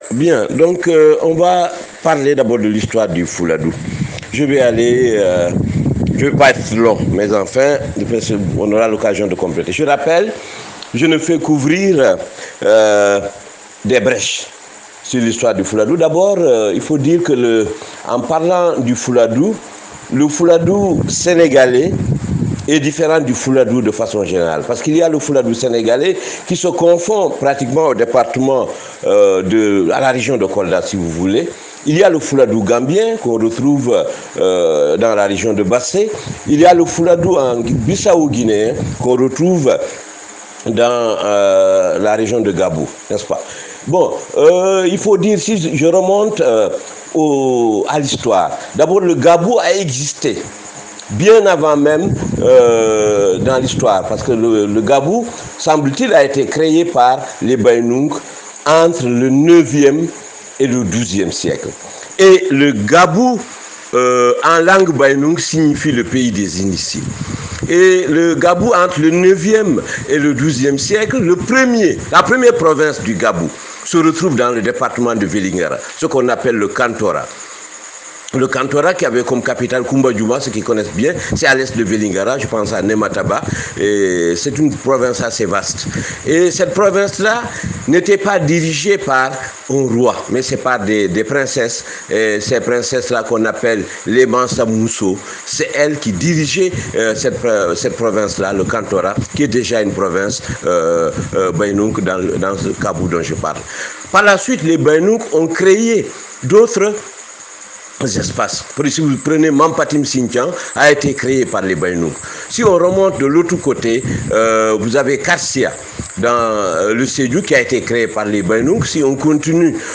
Ici quelques audios de l’exposé